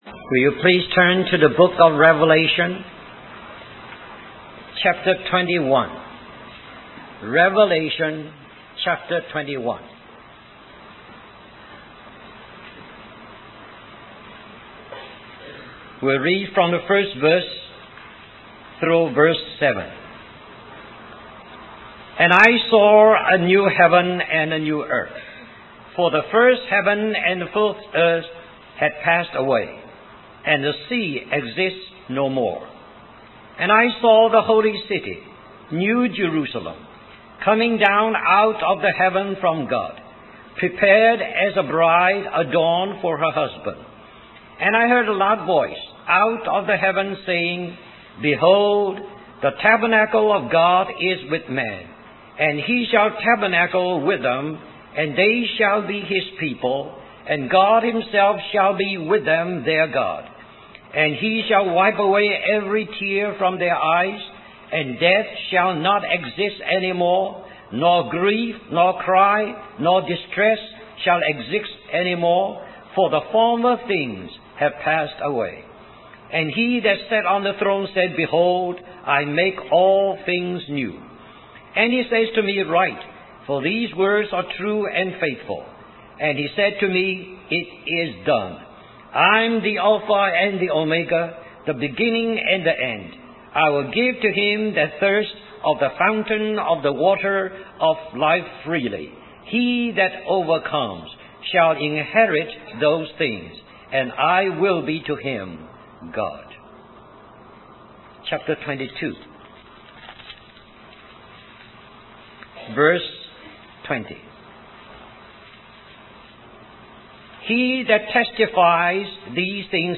In this sermon, the speaker emphasizes that God's will is being fulfilled in time, despite occasional setbacks. The work of redemption was completed through Jesus' crucifixion and the outpouring of the Holy Spirit.